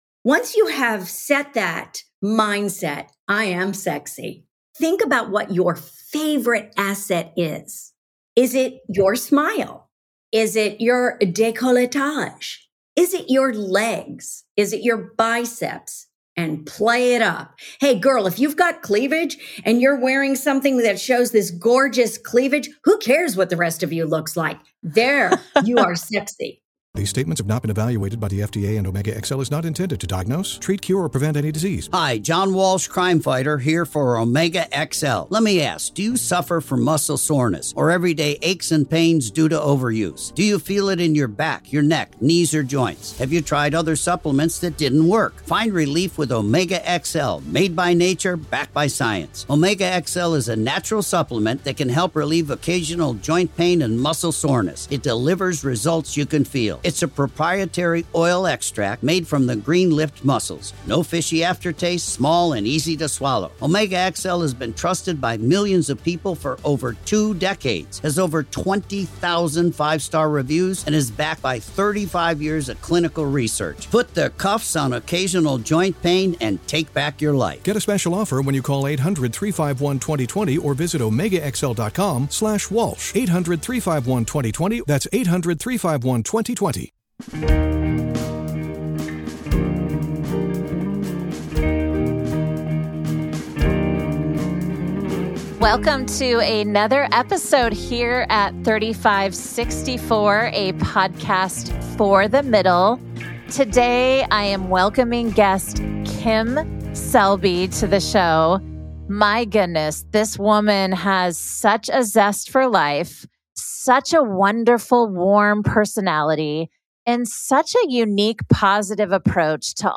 Tune in for an uplifting conversation on inner beauty and the power of a positive mindset at any age.